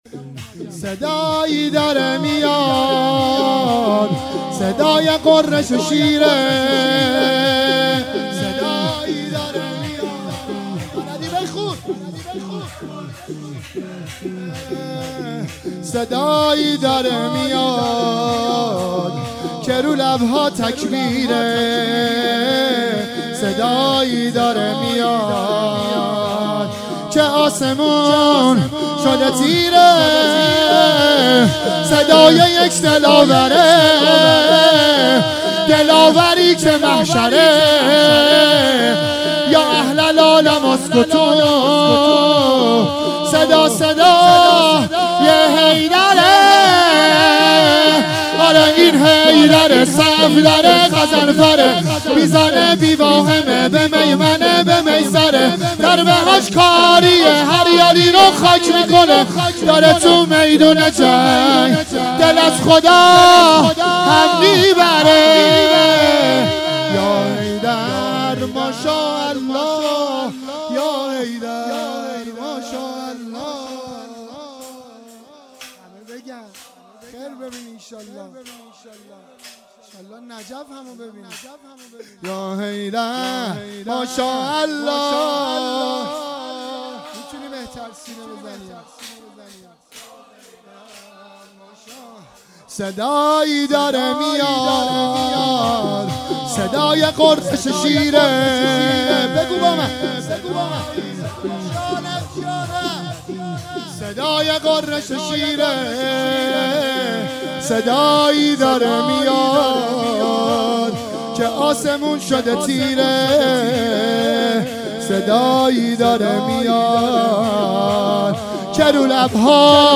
شور
روضه انصارالزهرا سلام الله علیها
روضه هفتگی